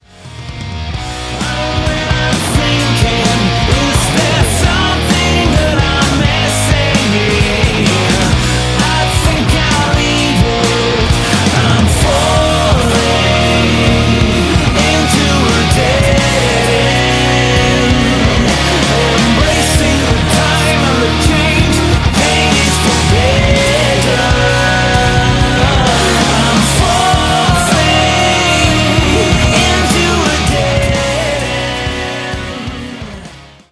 RETRO MODERNIST ALT-METAL